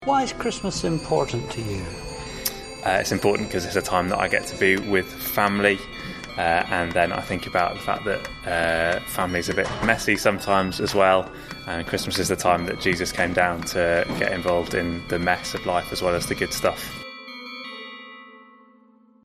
A student from Durham tells us why Christmas is important to him.